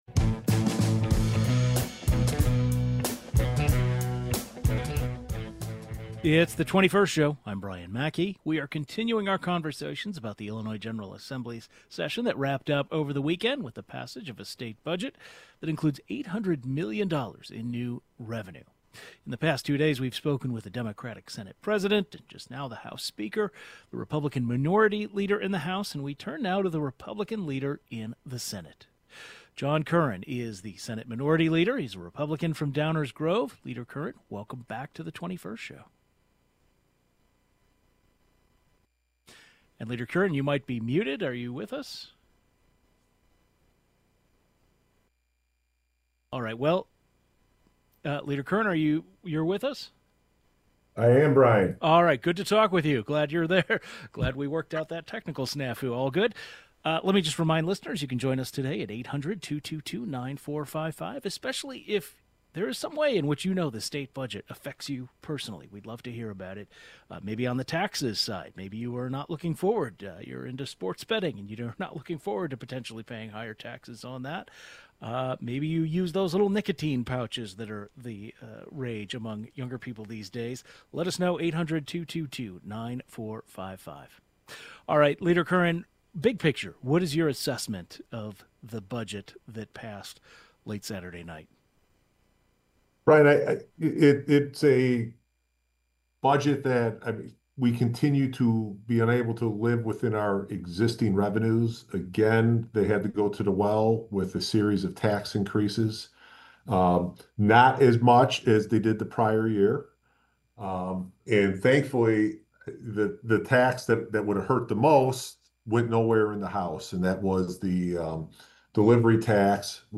John Curran, who leads the Senate Republican caucus, joins the program to talk about the Republican perspective on the recently passed budget.